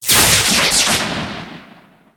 energy.ogg